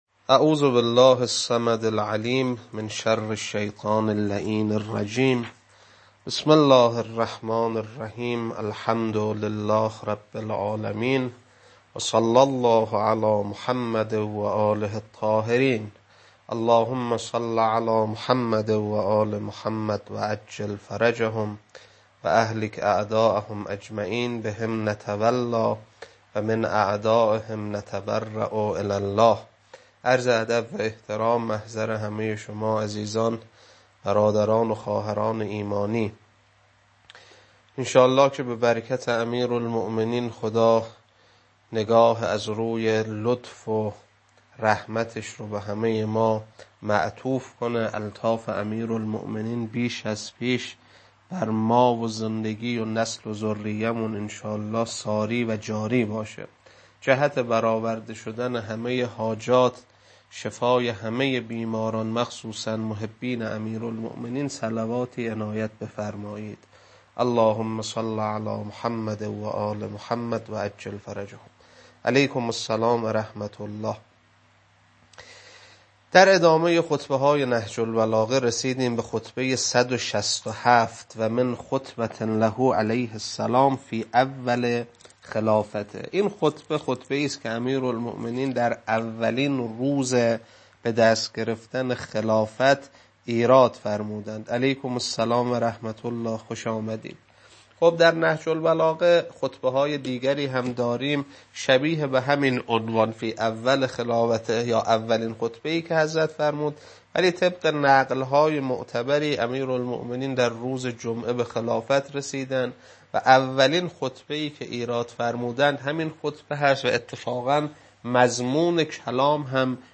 خطبه 167.mp3
خطبه-167.mp3